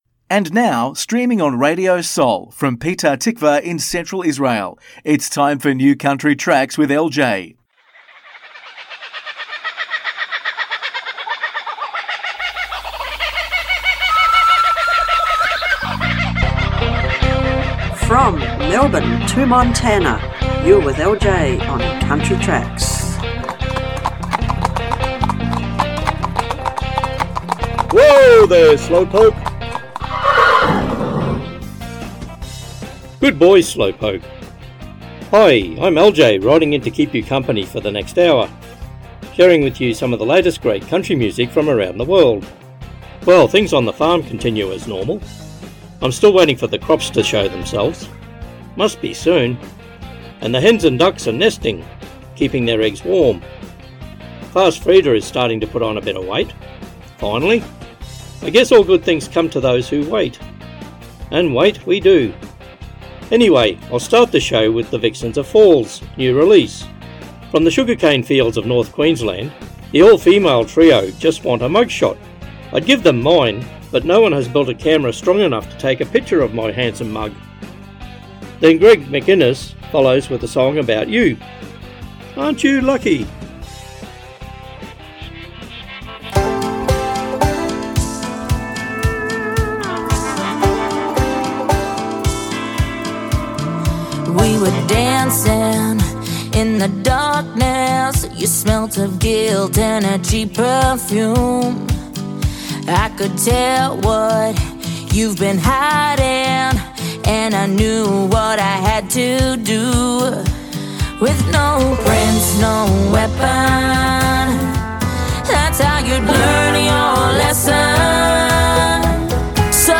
מוזיקת קאנטרי ואינדי עולמית - התכנית המלאה 6.12.24